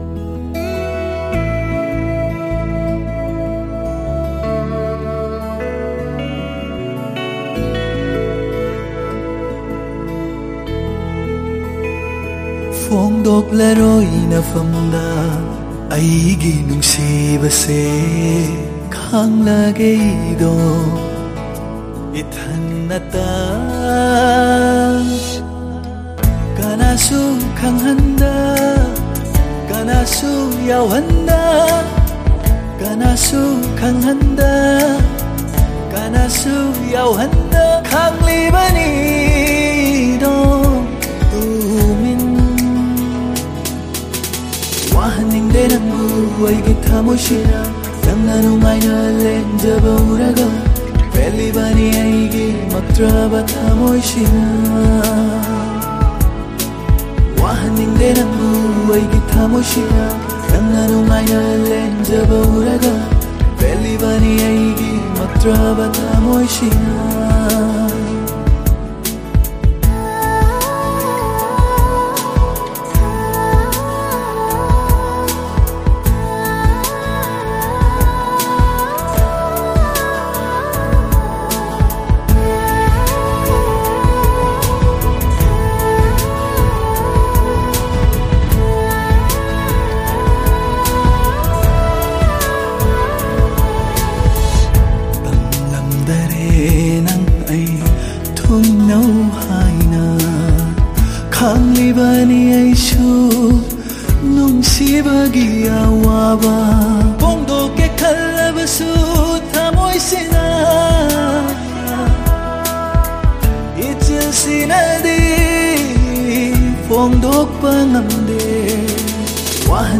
a Manipuri Music Video Album.